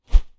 Close Combat Swing Sound 9.wav